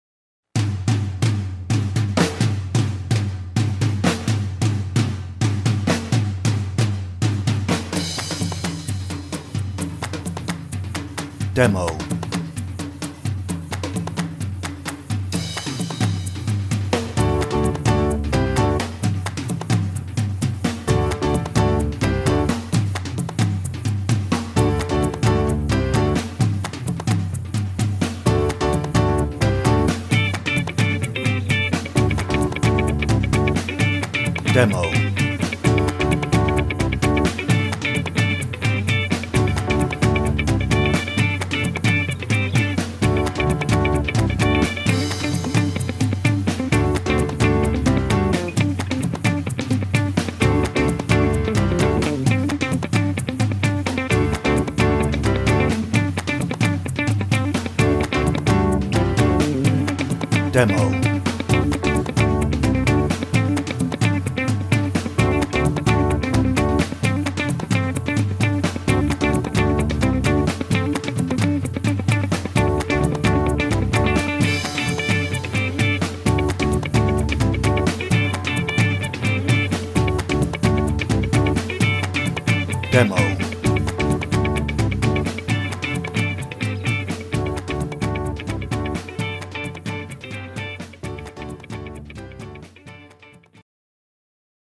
No ref. vocal
Instrumental